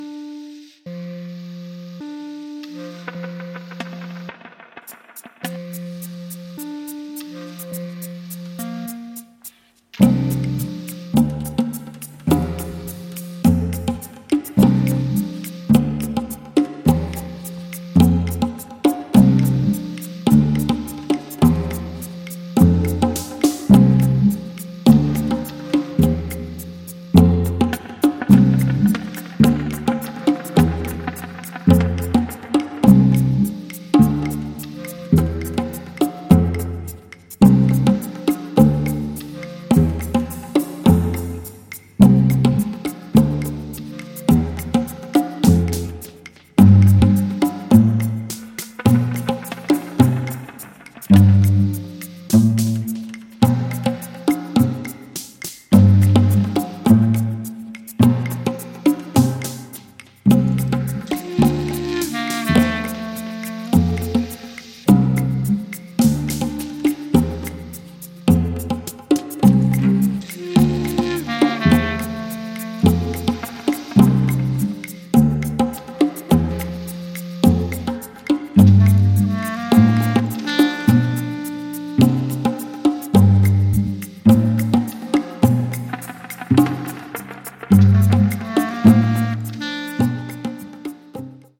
Techno